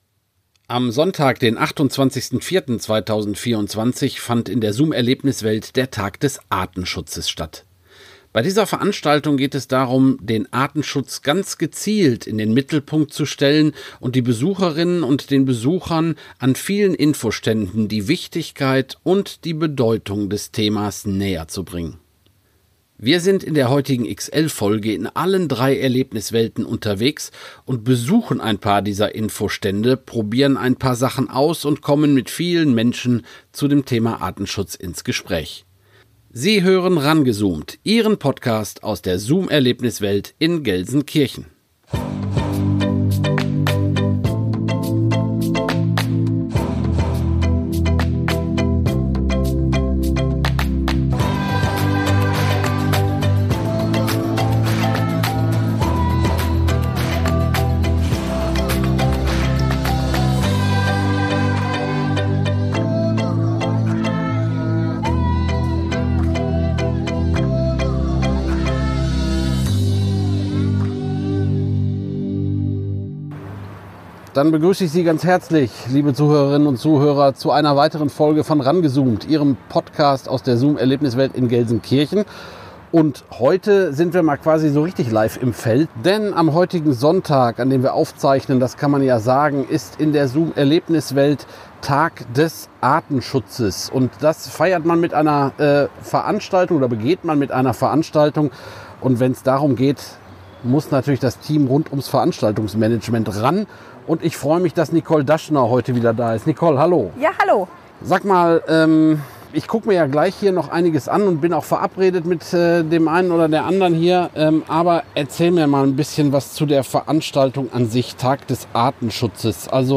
Beschreibung vor 1 Jahr Am 28. April fand in der ZOOM Erlebniswelt der Tag des Artenschutzes statt.